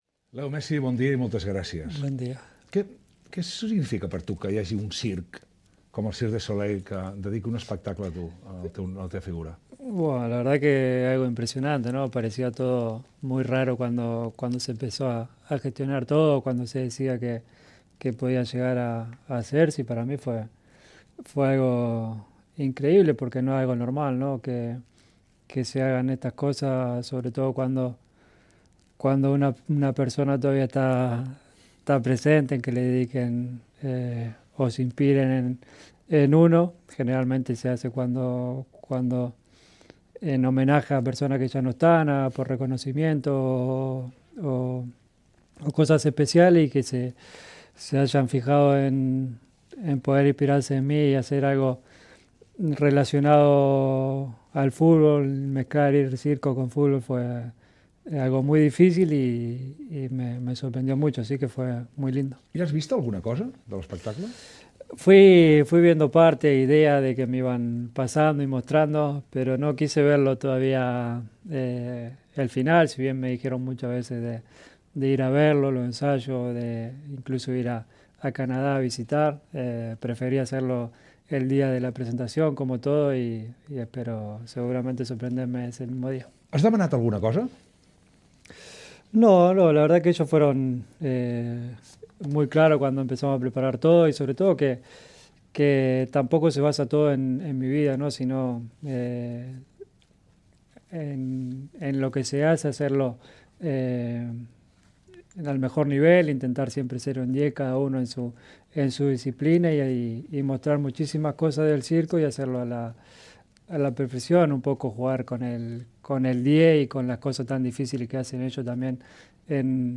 S'hi parla de l'espectacle del Cirque du Soleil "Messi 10", de la seva vida personal, dels problemes amb hisenda, del Futbol Club Barcelona... Gènere radiofònic Info-entreteniment